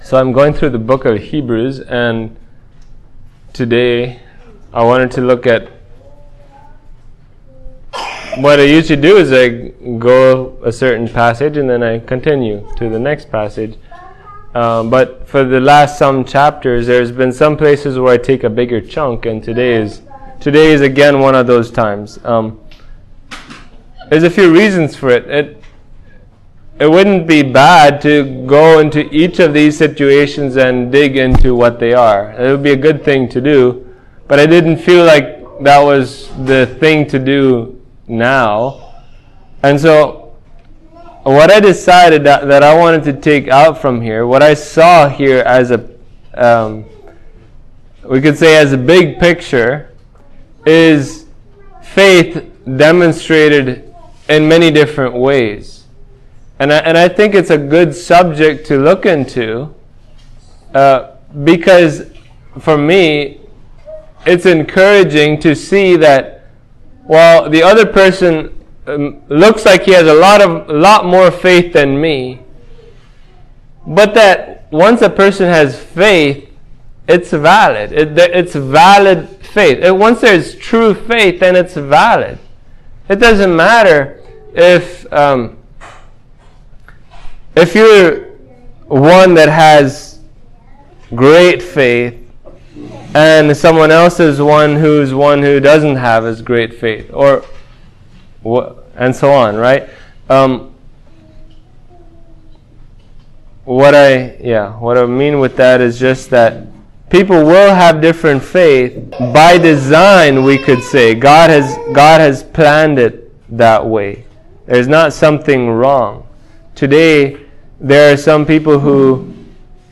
This sermon on the great chapter on faith, Hebrews 11, seeks to unpack this crucial point.
Heb 11:20-40 Service Type: Sunday Morning All true faith causes us to trust God no matter the circumstances.